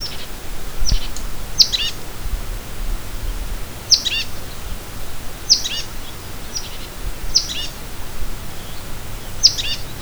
Sounds of the Escondido Creek Watershed
Bushtit Territorial